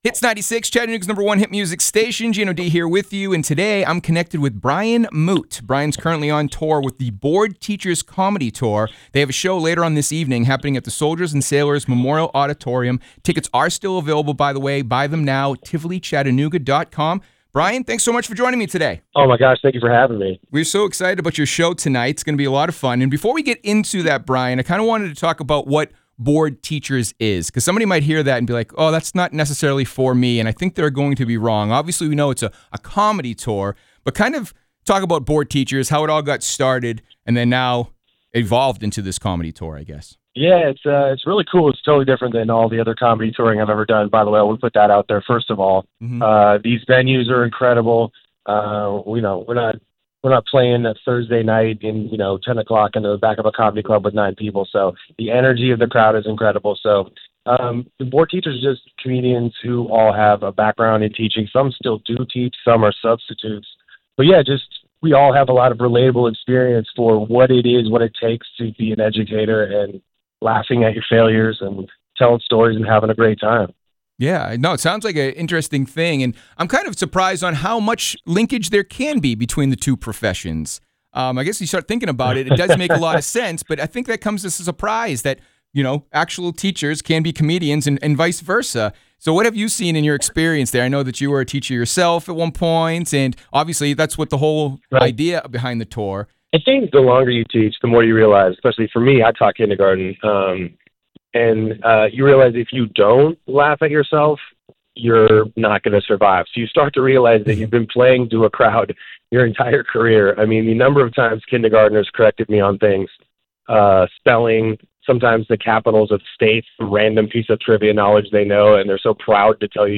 Full-Interview.wav